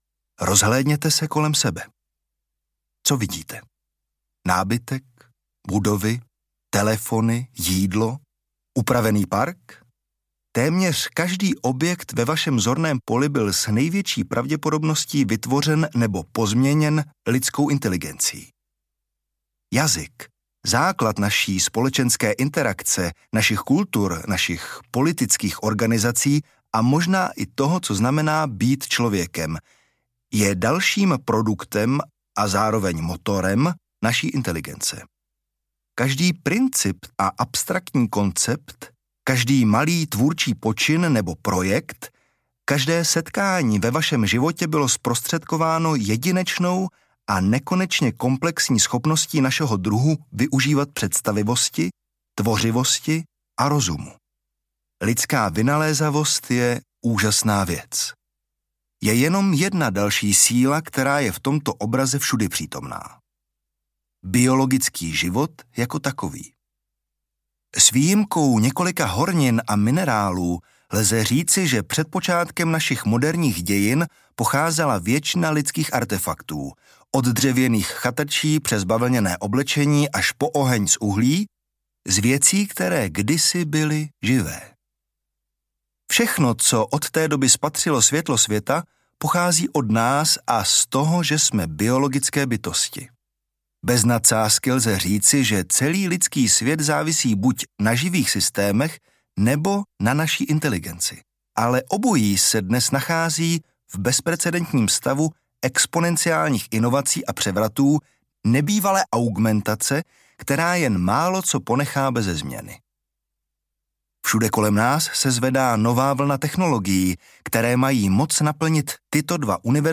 Mustafa Suleyman, spoluzakladatel DeepMind, představuje svůj pohled na technologickou revoluci a její dopady na společnost. Audiokniha o budoucnosti s AI.